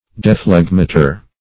Search Result for " dephlegmator" : The Collaborative International Dictionary of English v.0.48: Dephlegmator \De*phleg"ma*tor\, n. An instrument or apparatus in which water is separated by evaporation or distillation; the part of a distilling apparatus in which the separation of the vapors is effected.
dephlegmator.mp3